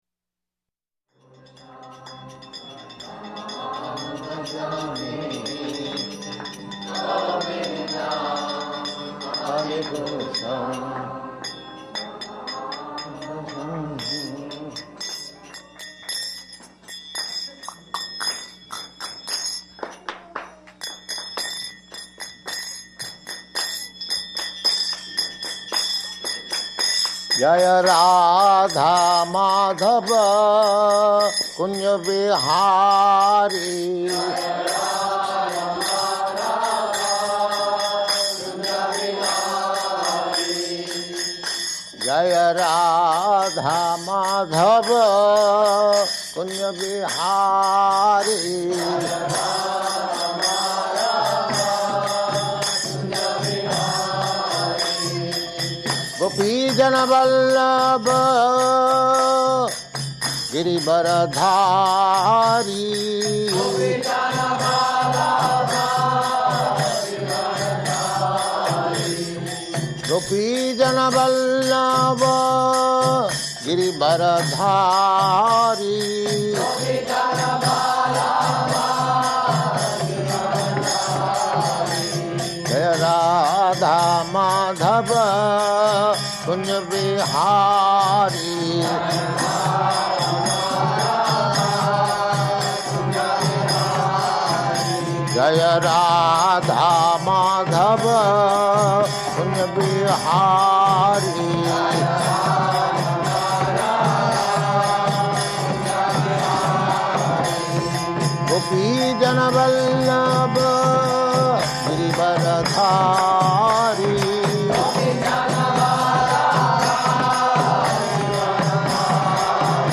Location: Los Angeles
[ Govindam prayers playing; sings along]